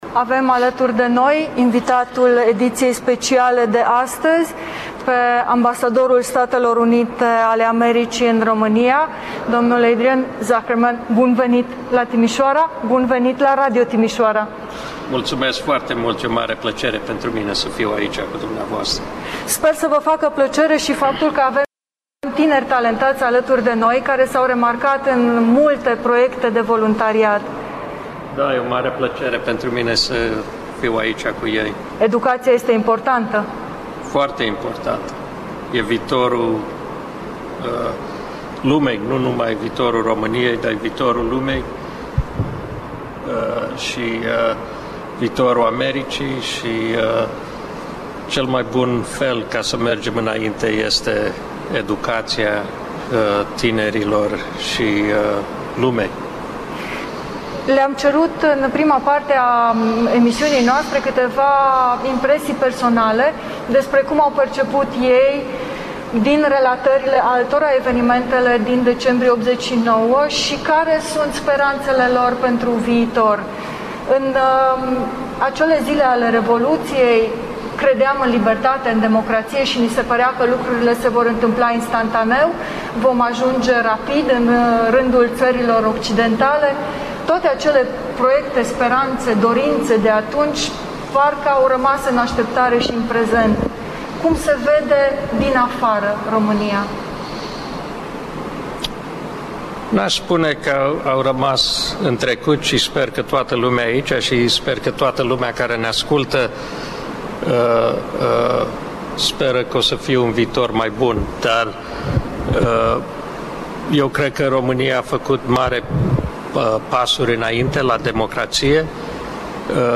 Ambasadorul SUA, prezent la Timișoara: Sacrificiul eroilor de la Revoluție nu a fost respectat în ultimii 10 ani/ Interviu - Radio România Timișoara